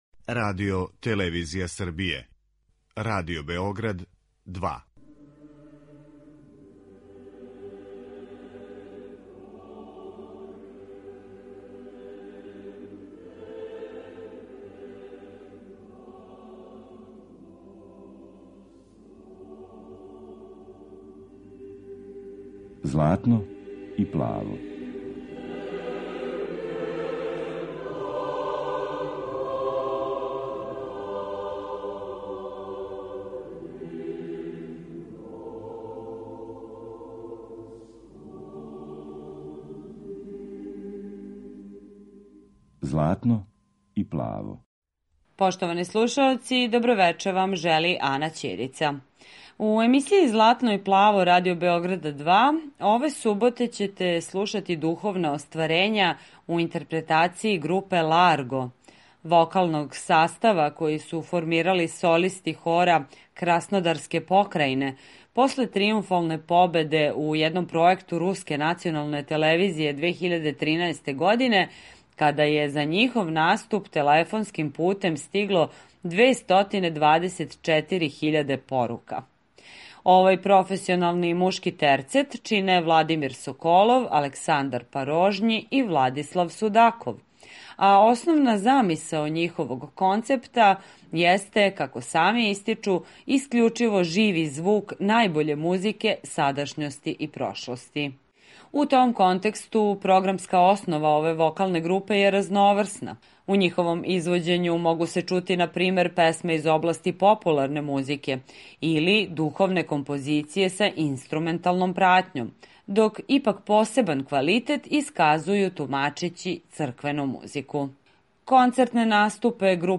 Извођење вокалне групе „Ларго”
Емитујемо духовна остварења руских аутора у интерпретацији професионалног мушког терцета који чине